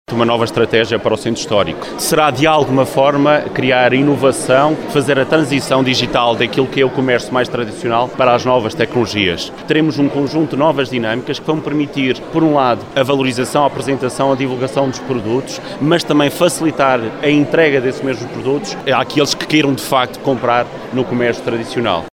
Alexandre Favaios, presidente da câmara de Vila Real, refere que esta é uma nova estratégia para o centro histórico criando mais valias para comércio tradicional: